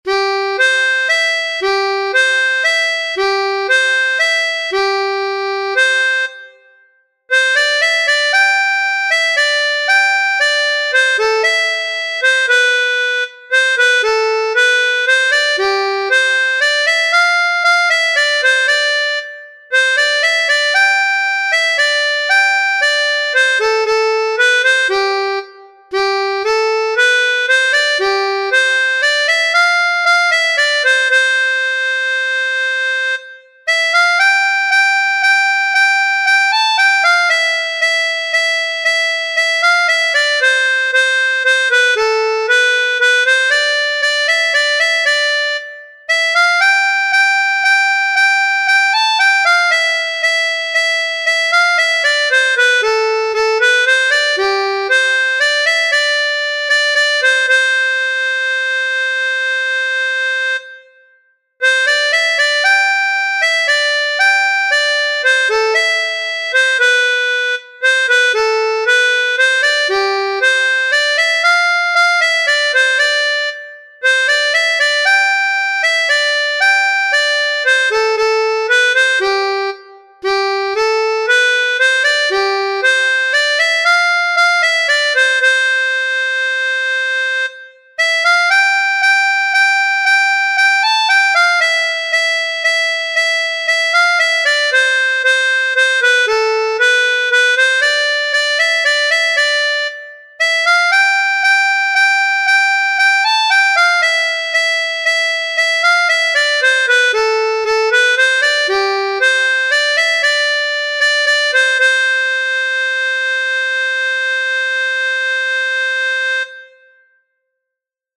2.1. C Major（C 大调）乐谱